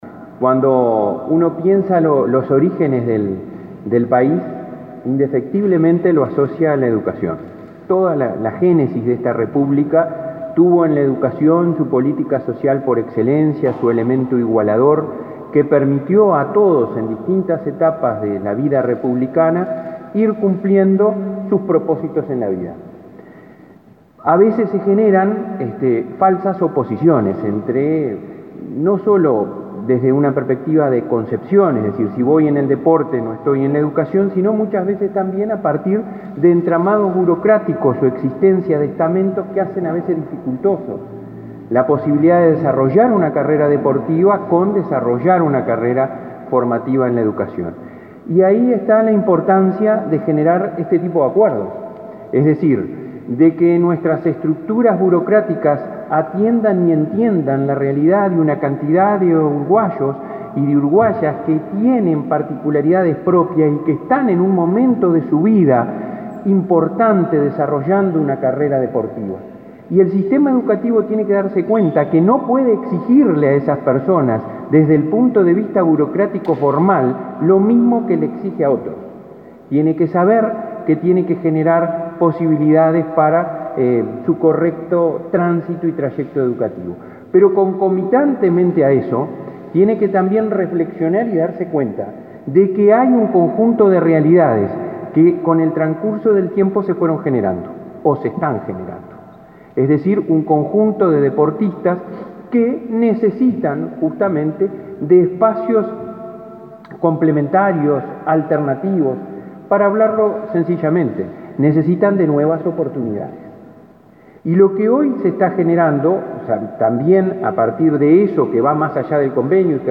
Palabras del presidente de la ANEP, Robert Silva